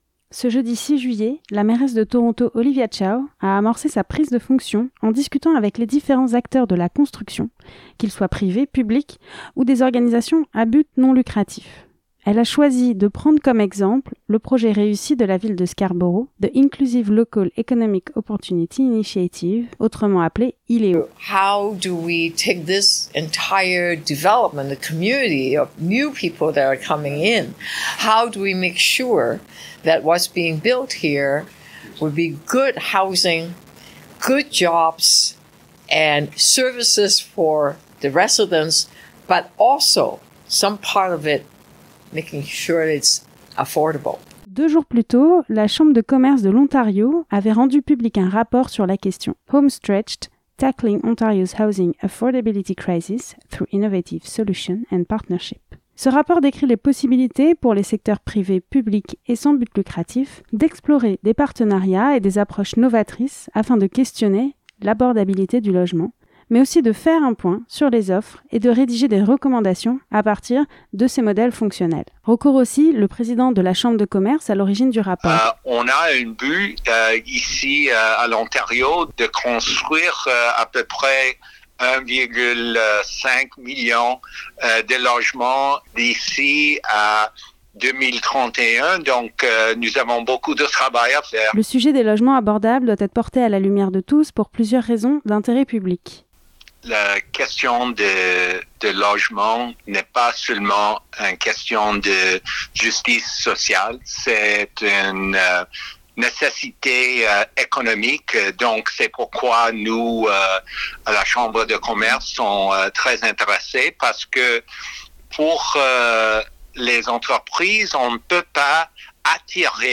Le reportage